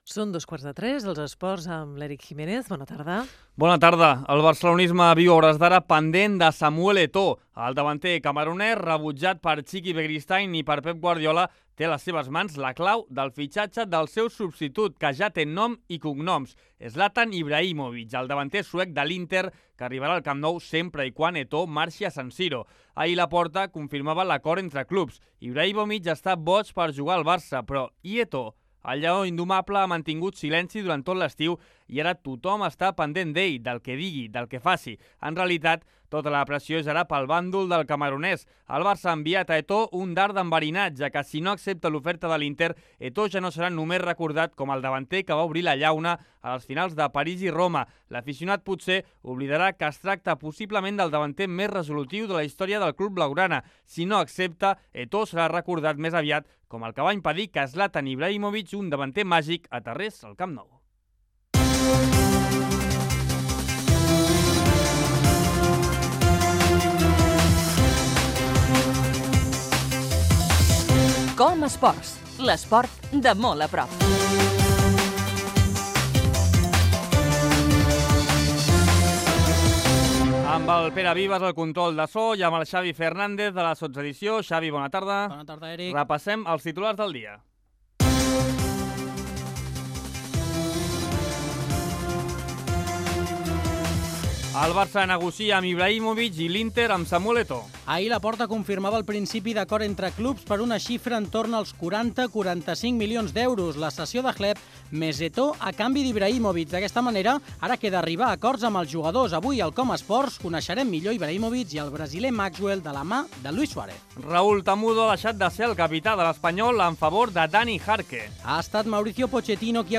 Fragment d'una entrevista a Luis Suárez.
Esportiu